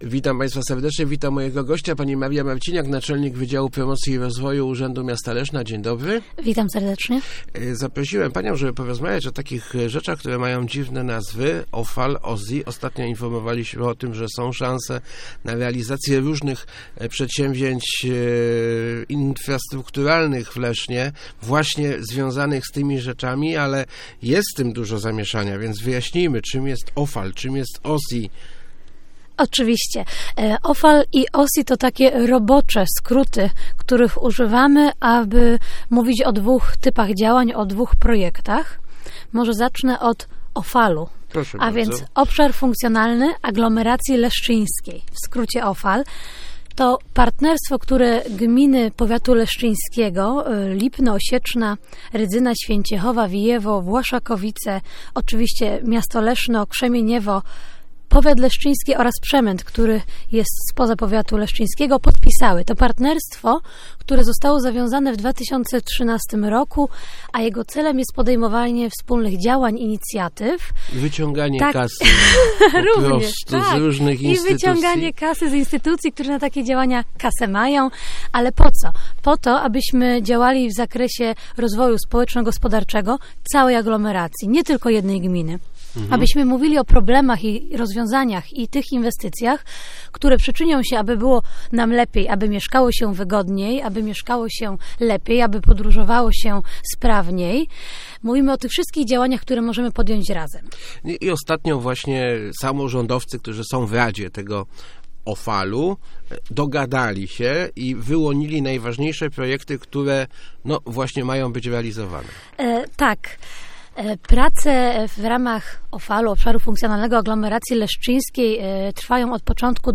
Start arrow Rozmowy Elki arrow OFAL, OSI, o co chodzi?